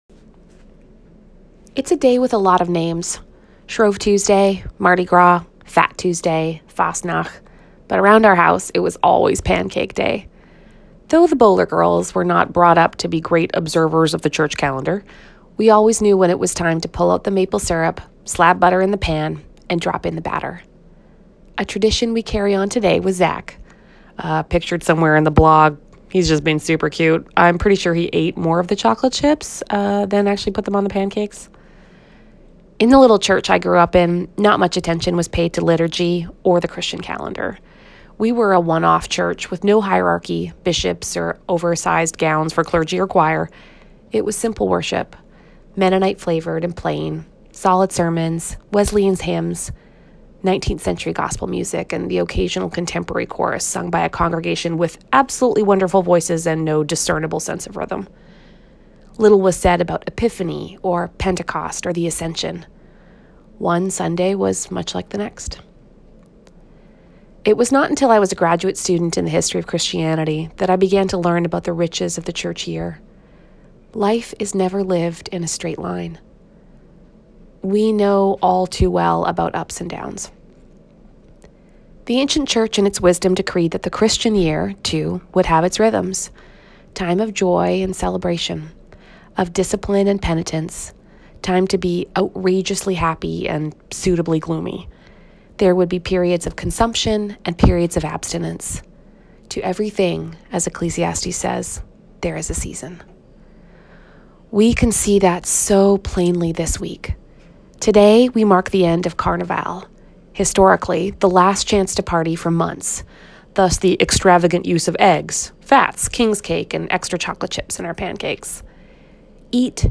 [Listen to a recording of today’s article by clicking play below.]